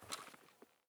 Weapon Cover Tilt / gamedata / sounds / wct / rattle / raise / raise_10.ogg